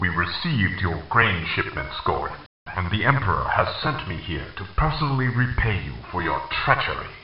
Darth Vader, to Ghorin — (audio)